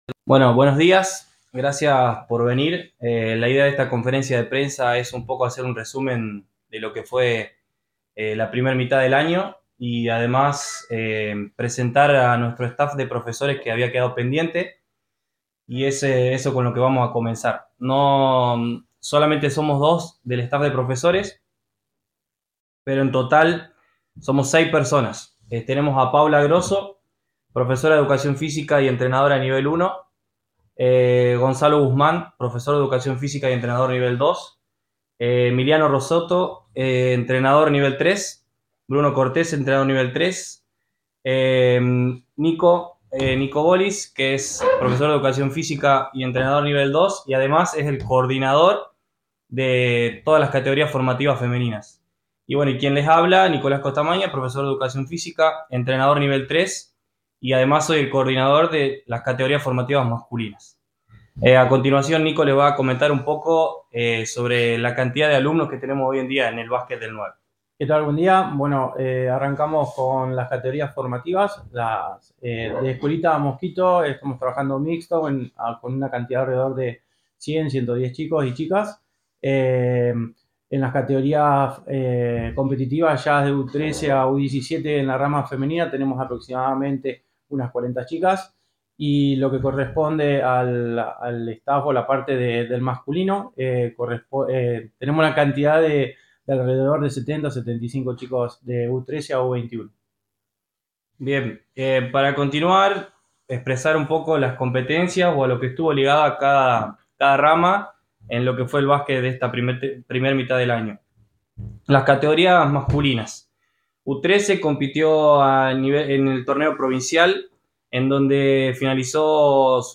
CONFERENCIA DE PRENSA
CONFERENCIADEPRENSA9.mp3